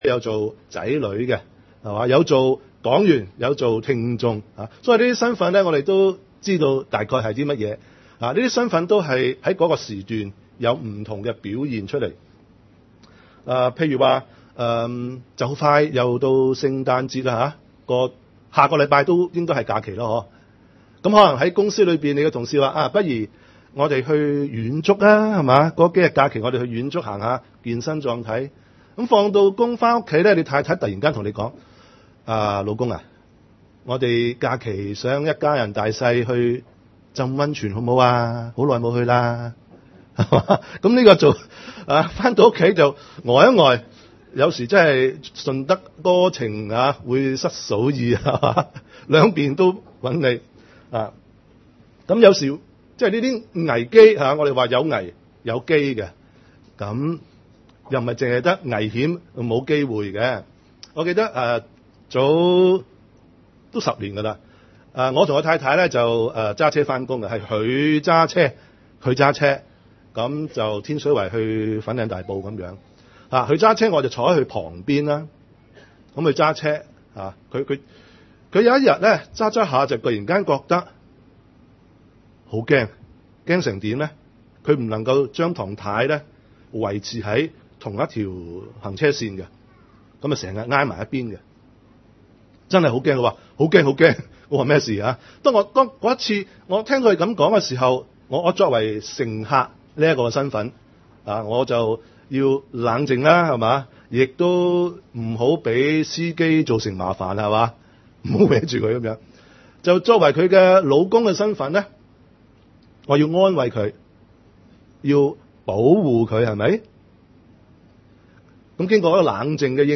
福音聚會：身份‧危‧機